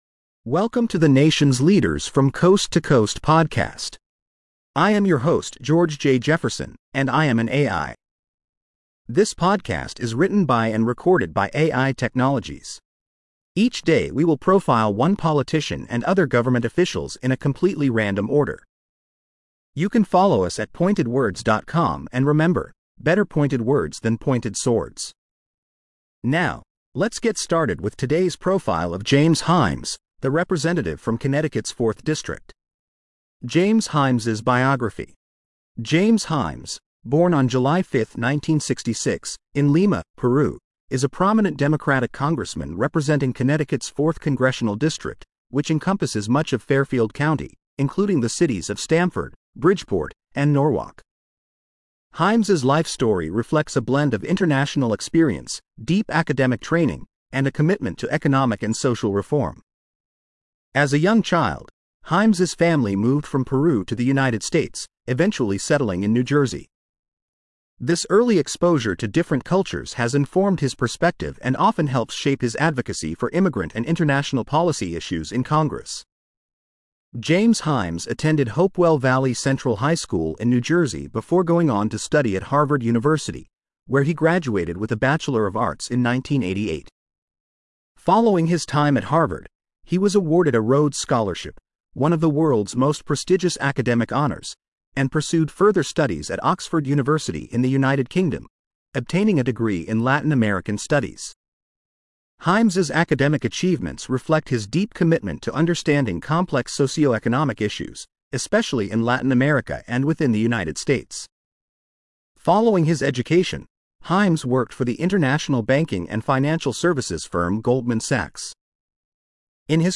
Listen to his full AI generated profile.